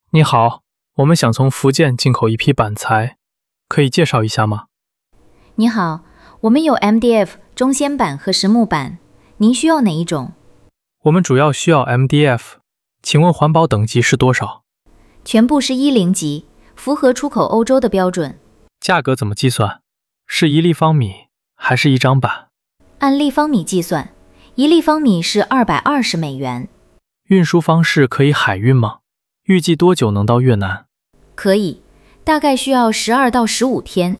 Hội thoại 1: Khi nhập nguyên liệu gỗ từ Quảng Đông, Phúc Kiến